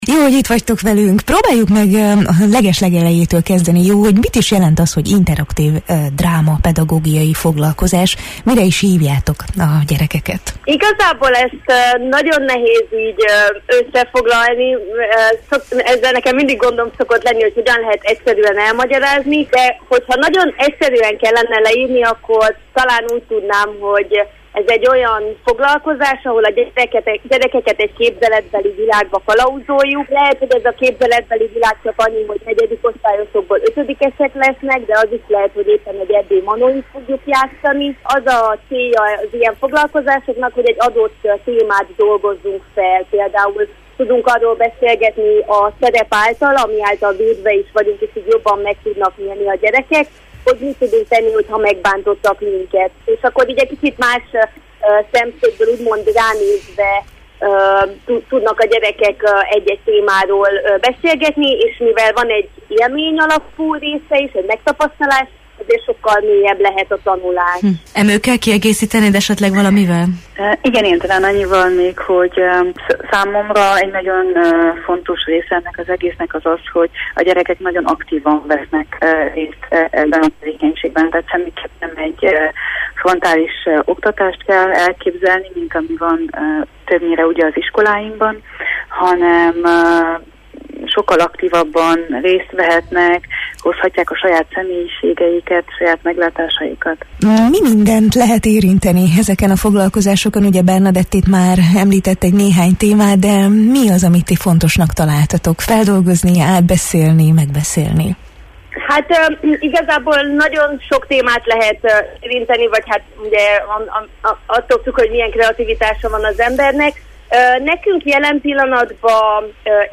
drámapedagógusokat kérdeztünk a Jó reggelt, Erdély!-ben: